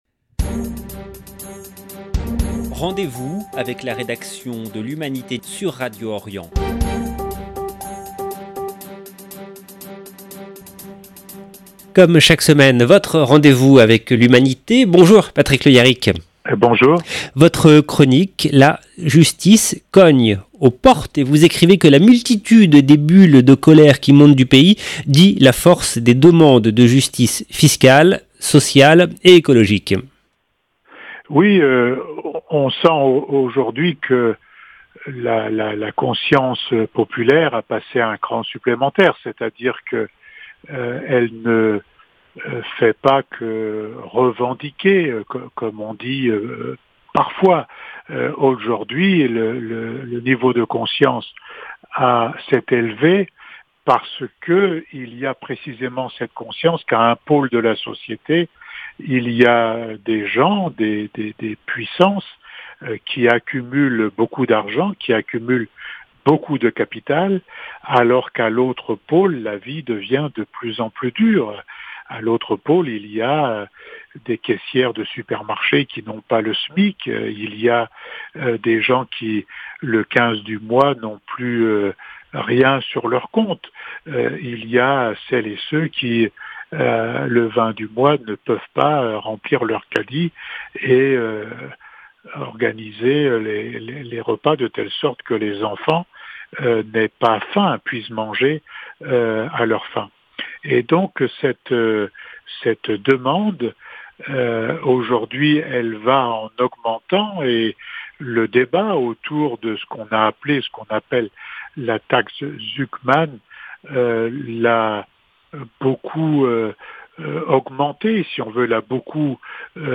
Chronique de l'Humanité du 02 octobre 2025